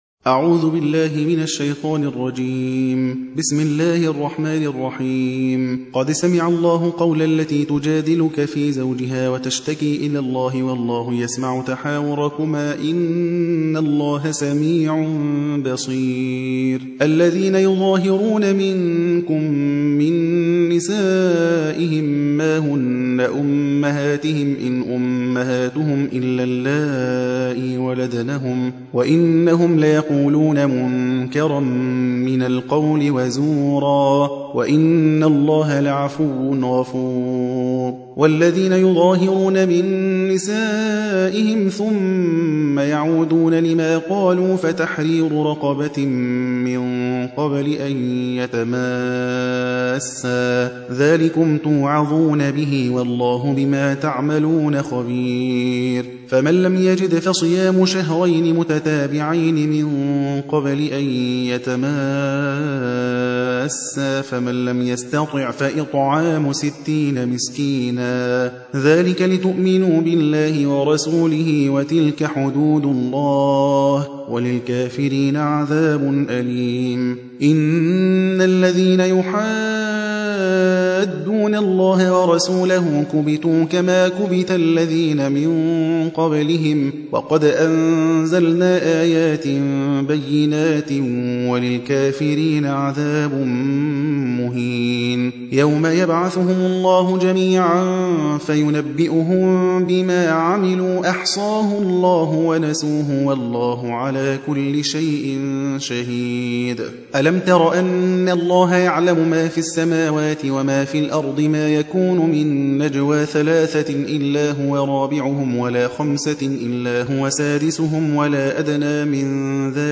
58. سورة المجادلة / القارئ